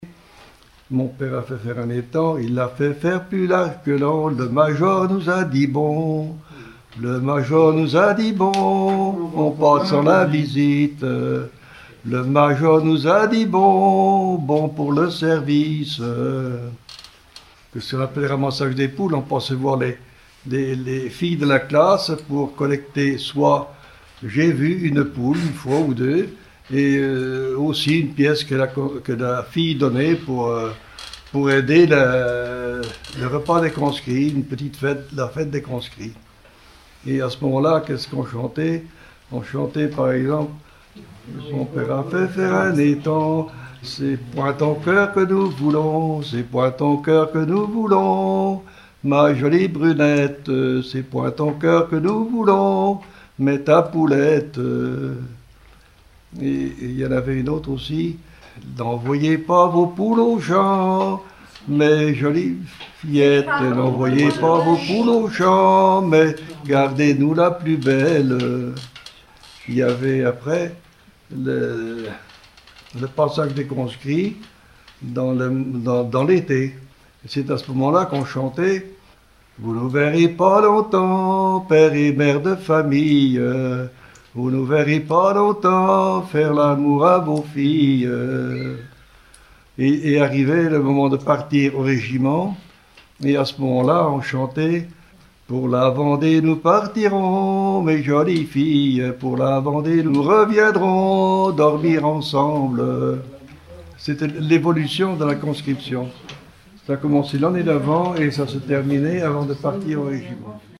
Genre laisse